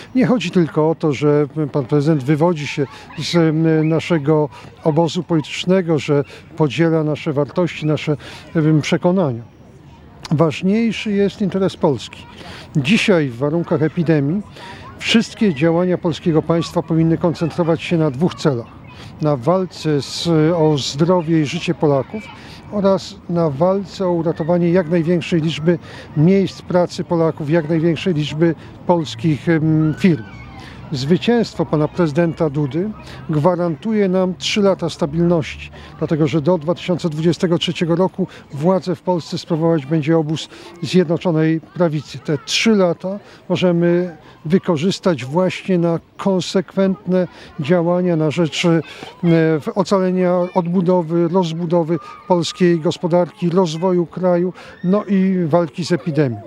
Jarosław Gowin, lider „Porozumienia” gościł w czwartek (09.07.20) w Suwałkach. Podczas krótkiego wystąpienia oraz spaceru po Placu Marii Konopnickiej zachęcał do głosowania w drugiej turze wyborów prezydenckich.